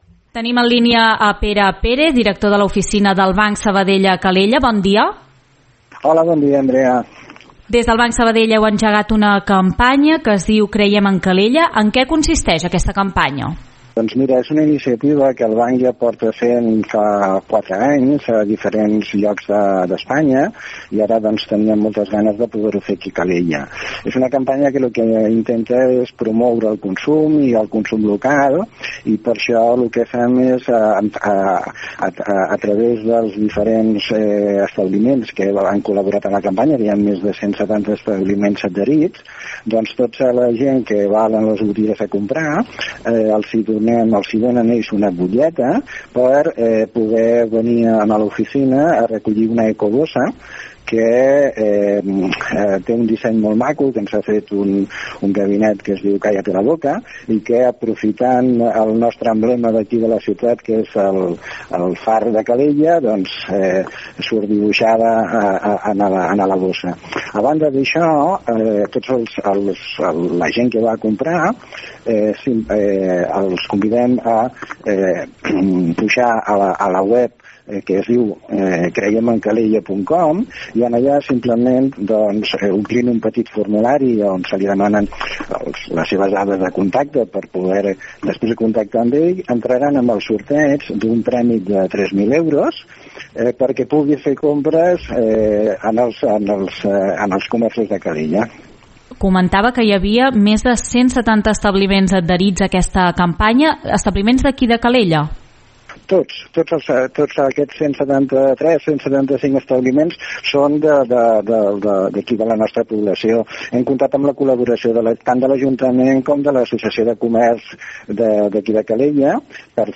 2245-ENTREVISTA-BANC-SABADELL.mp3